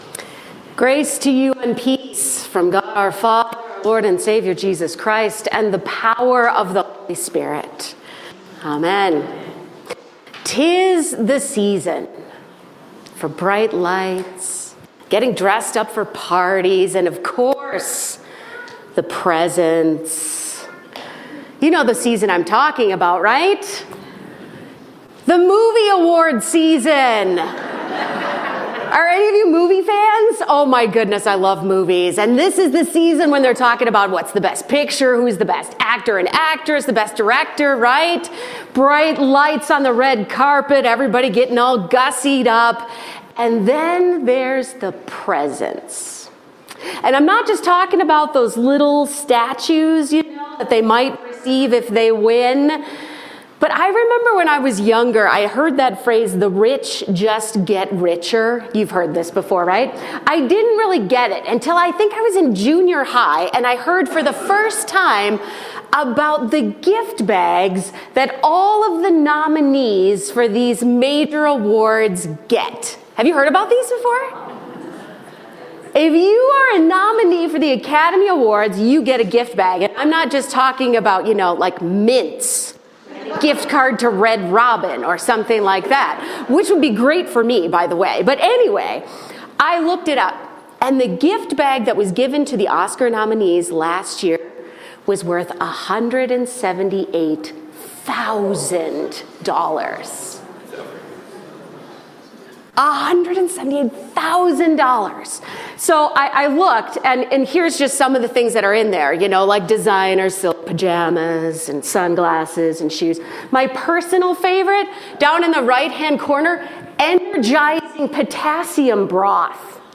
John 2:13-25 Service Type: Sunday Morning